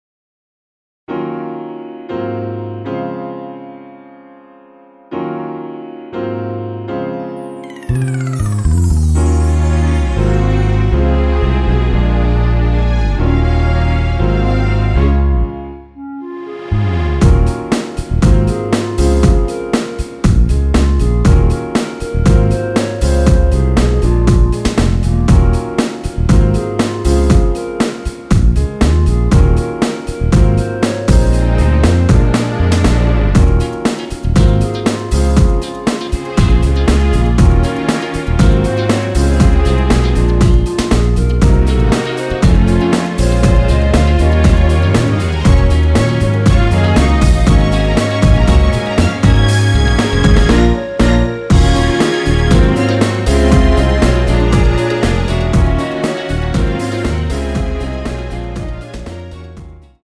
원키에서(-1)내린 멜로디 포함된 MR입니다.
Db
◈ 곡명 옆 (-1)은 반음 내림, (+1)은 반음 올림 입니다.
앞부분30초, 뒷부분30초씩 편집해서 올려 드리고 있습니다.